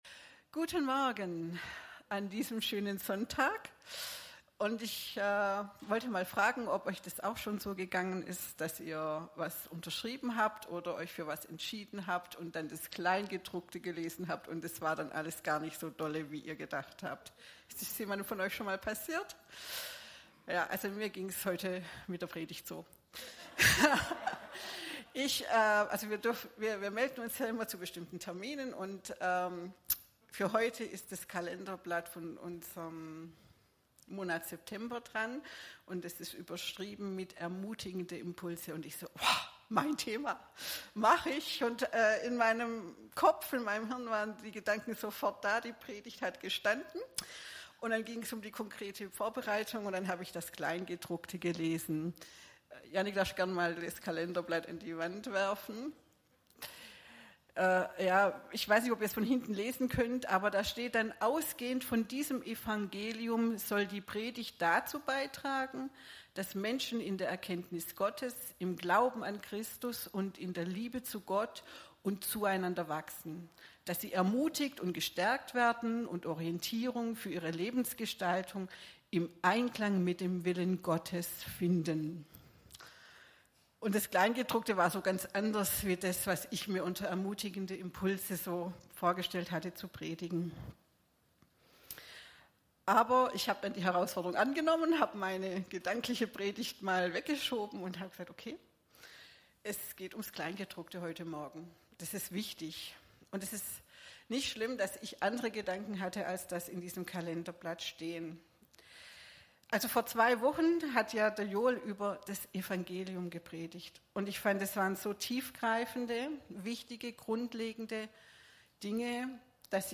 Predigten - Christusgemeinde Nagold